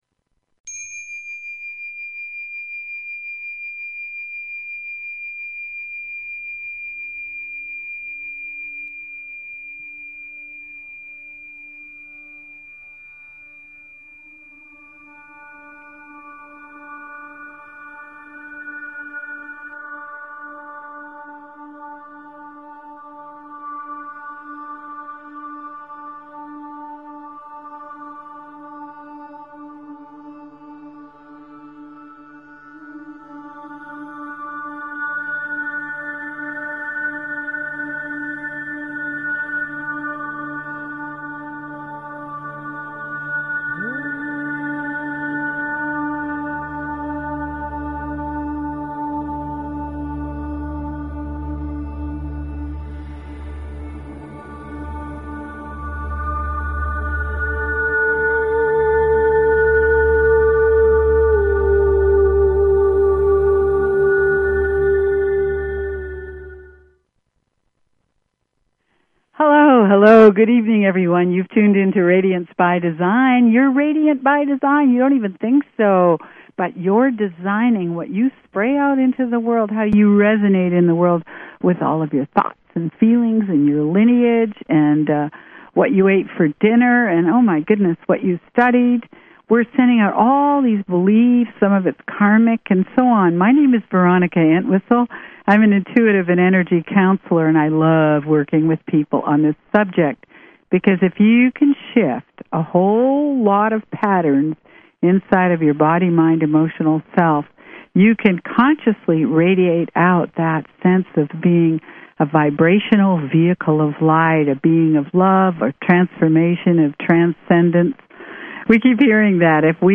Talk Show Episode, Audio Podcast, Radiance_by_Design and Courtesy of BBS Radio on , show guests , about , categorized as
The show offers you a much needed spiritual tune up – gives you the means to hold your own as you engage the crazy dynamics that occupy our ever changing planet. Radiance By Design is specifically tailored to the energies of each week and your calls dictate our on air discussions.